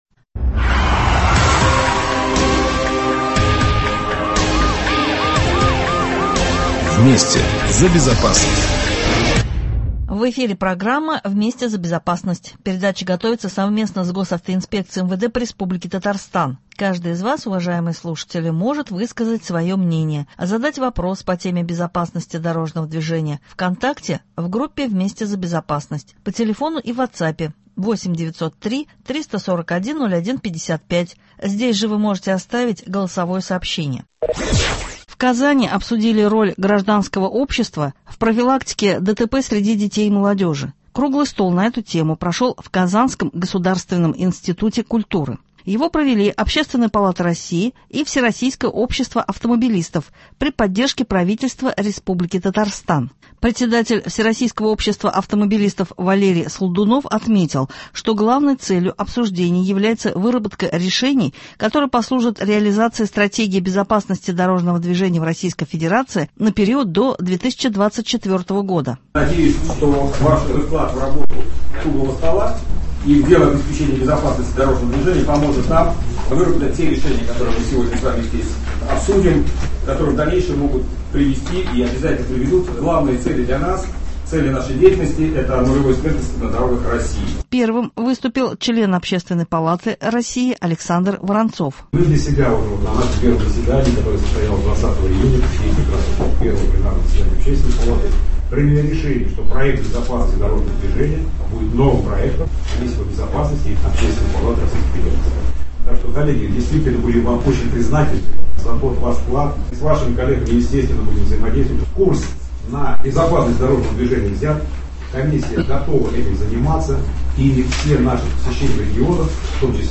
Круглый стол на эту тему прошёл в Казанском государственном институте культуры.